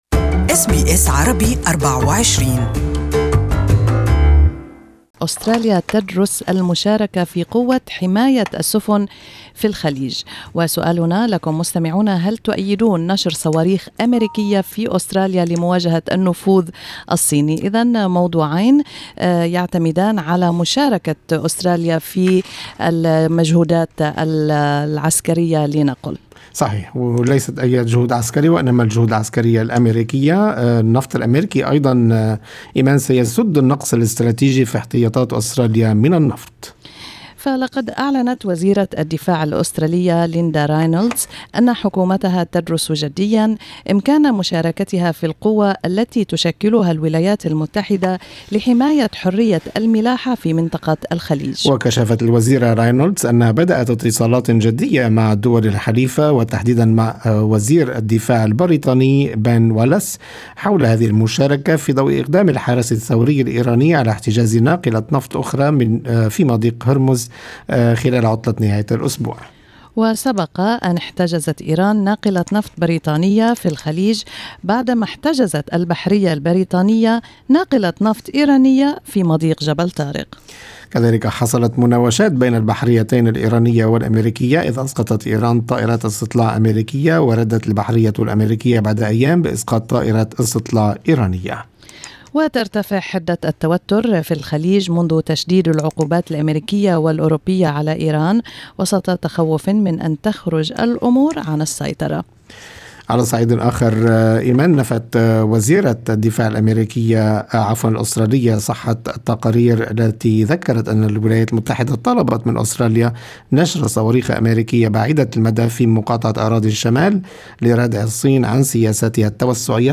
استمعوا إلى الآراء في الرابط الصوتي أعلاه.